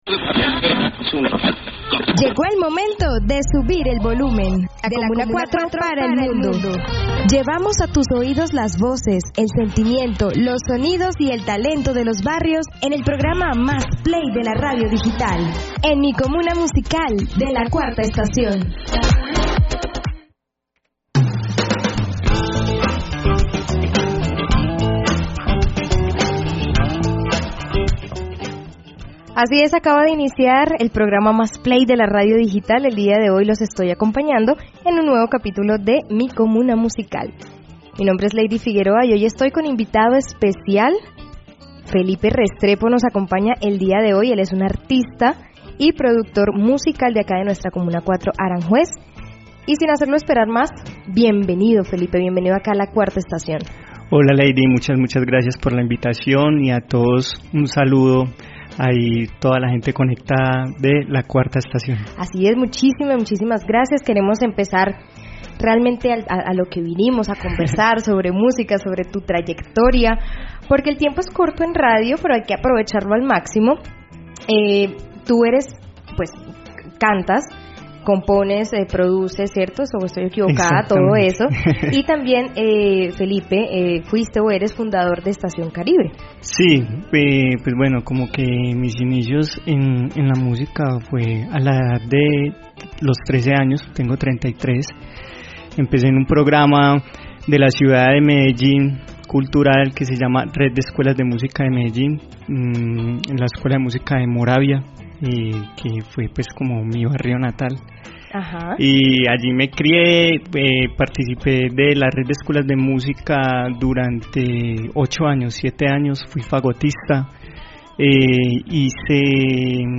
En esta entrevista, nos comparte las razones que lo llevaron a decidirse por el arte, su visión sobre el Afrobeat y mucho más.